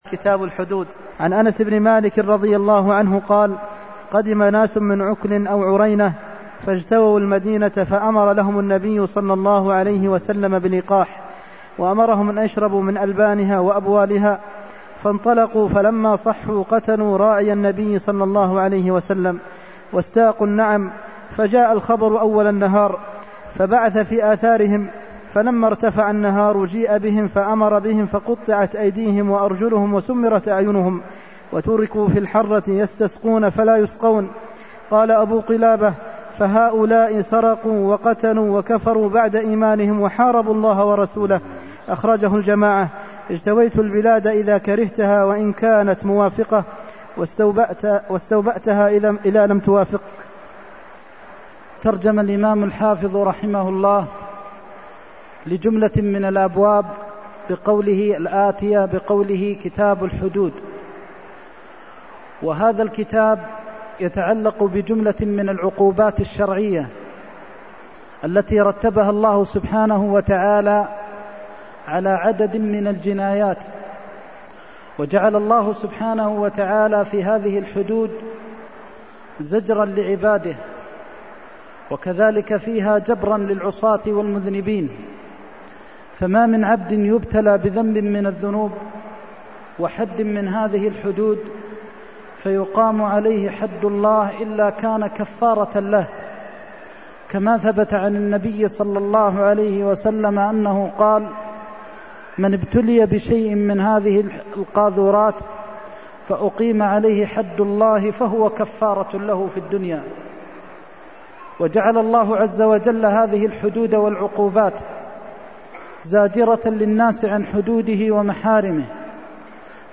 المكان: المسجد النبوي الشيخ: فضيلة الشيخ د. محمد بن محمد المختار فضيلة الشيخ د. محمد بن محمد المختار فلما صحوا قتلوا راعي النبي واستاقوا النعم (328)